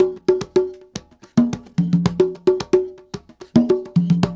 Funk Master Conga 03.wav